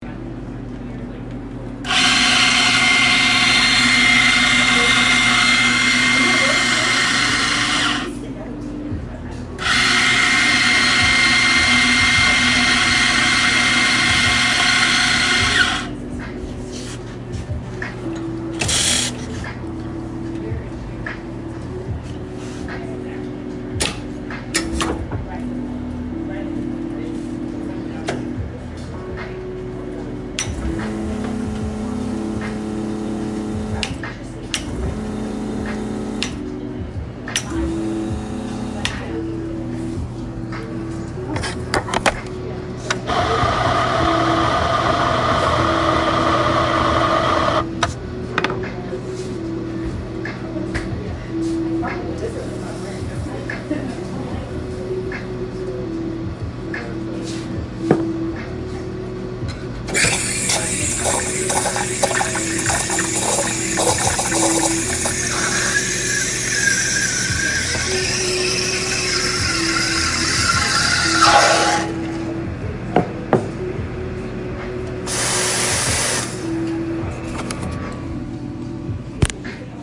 咖啡机 " 咖啡机以加热为起点
描述：心的咖啡机的开关。这是DeLonghi咖啡机。XYStereo.
标签： 厨房 咖啡机 噪音 咖啡
声道立体声